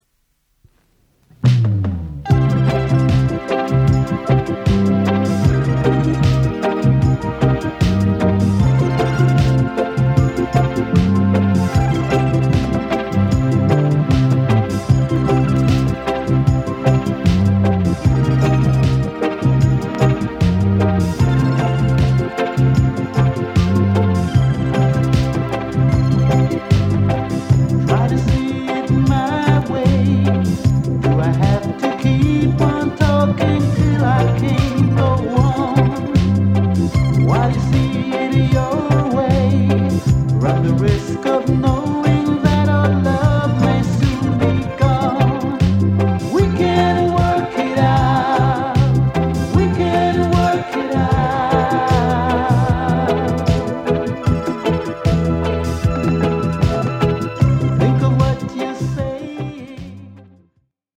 ソウルフル・レゲエ